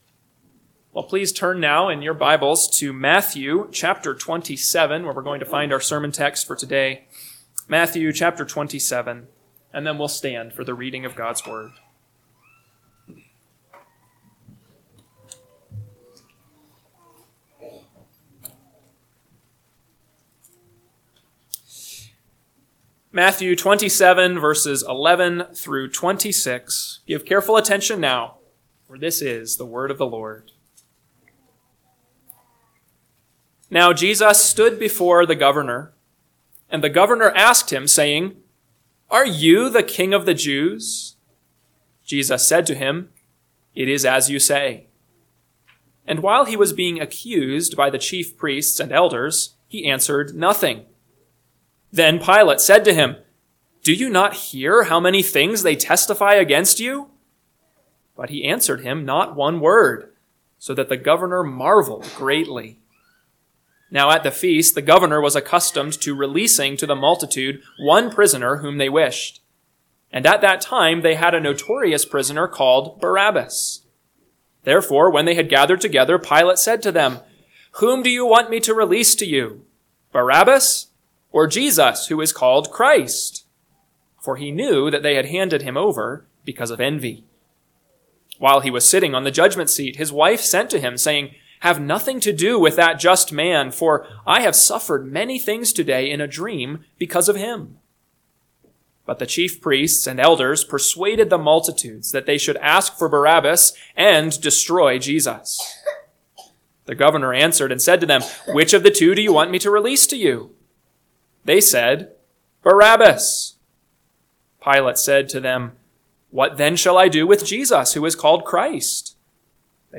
AM Sermon – 4/13/2025 – Matthew 27:11-26 – Northwoods Sermons